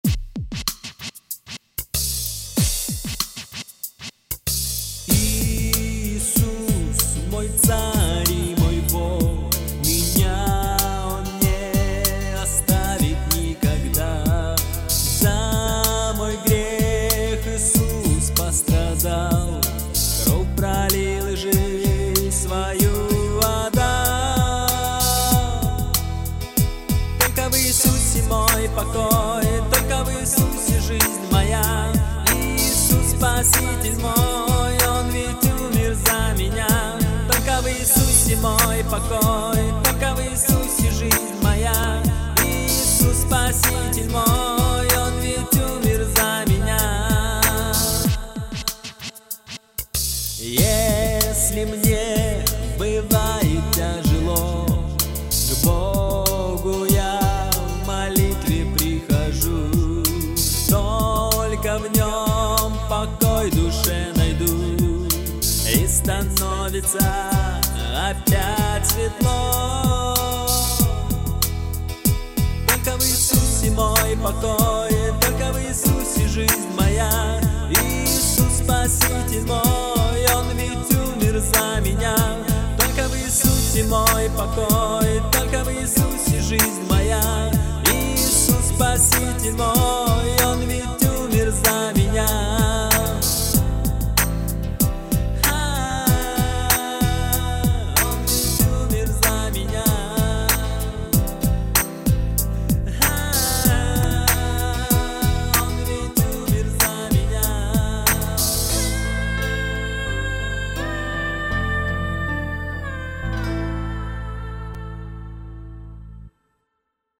песня
213 просмотров 270 прослушиваний 32 скачивания BPM: 95